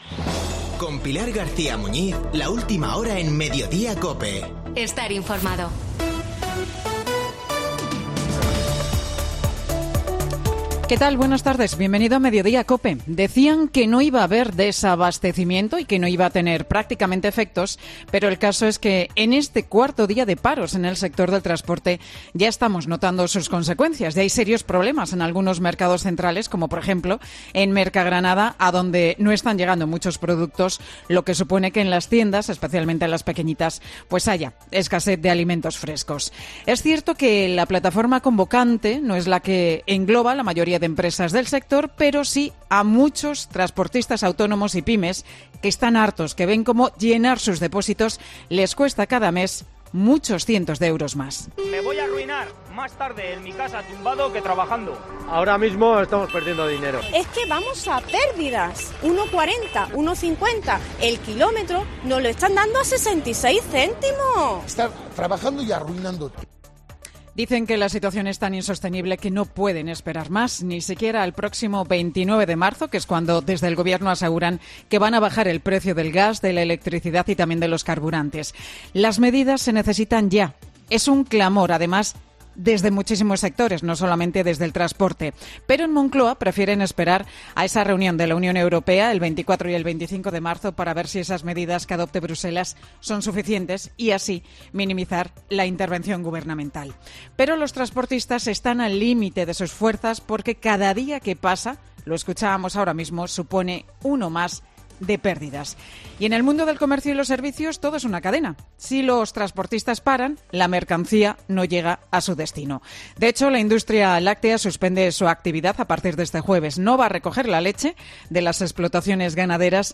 AUDIO: El monólogo de Pilar García Muñiz, en Mediodía COPE